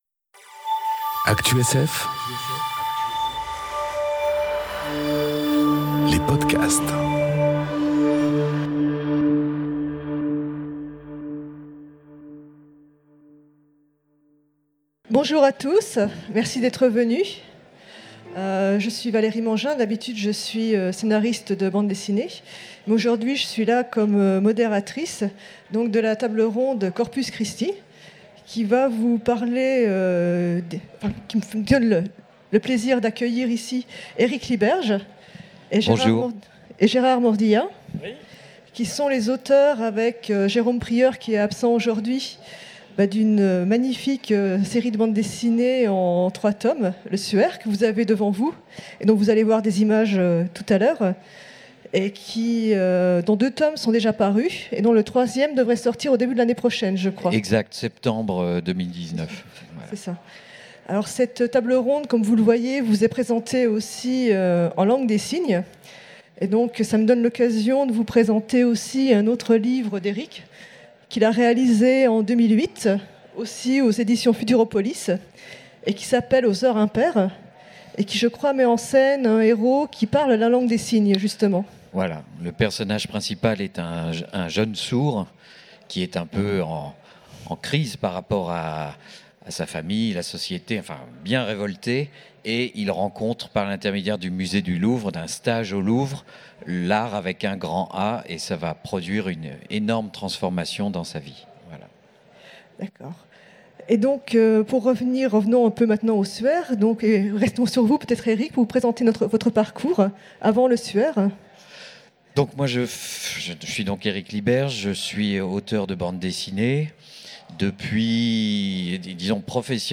Conférence Corpus Christi enregistrée aux Utopiales 2018